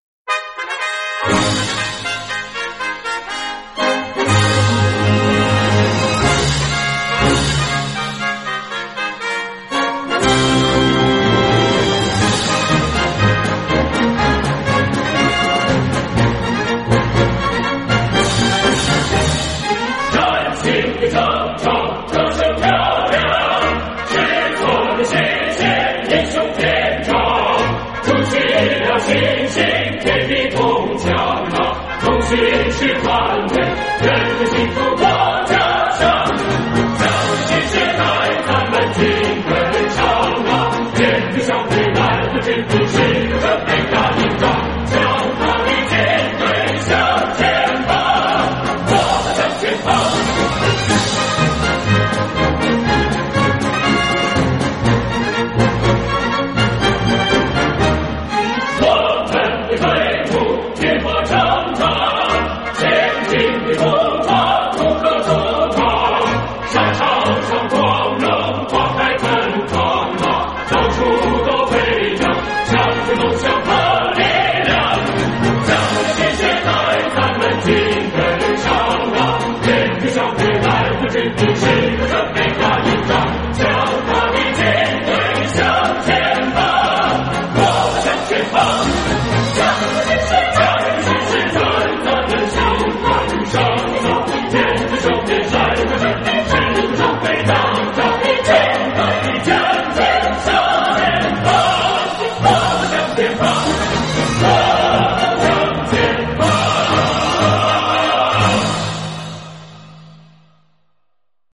演唱：合唱